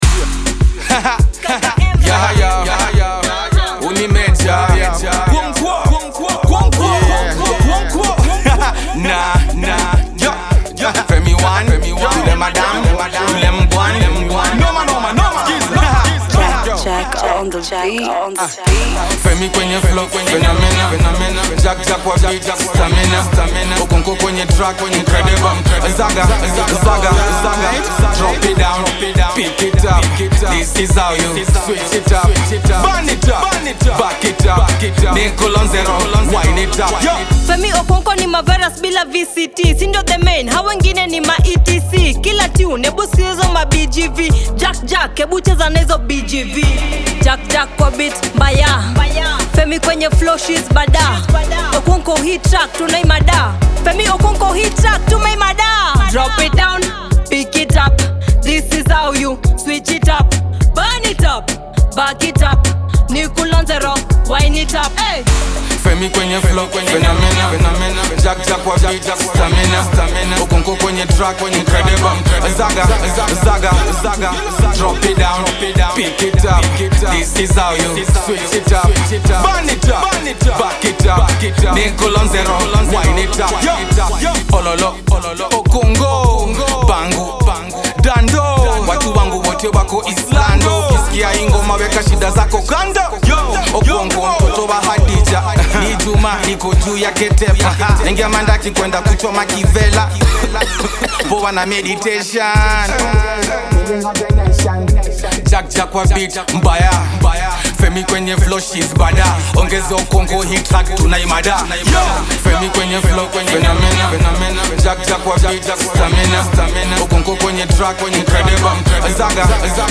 Kenyan femcee
The feel good song